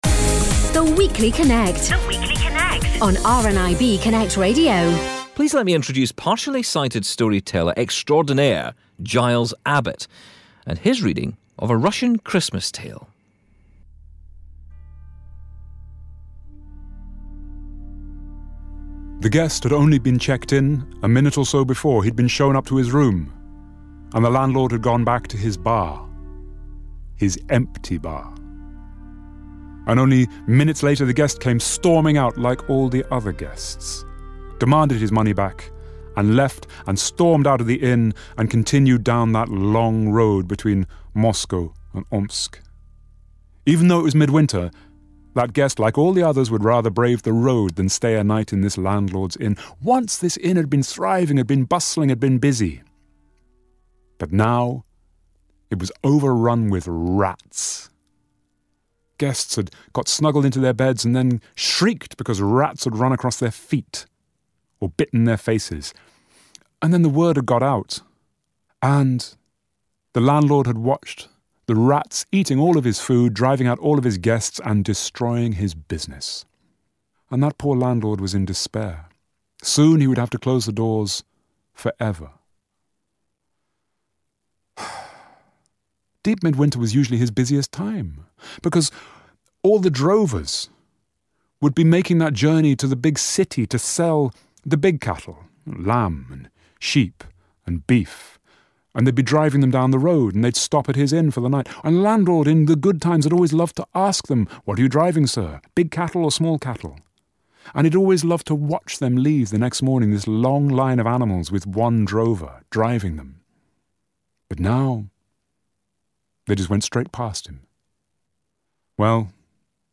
Storytelling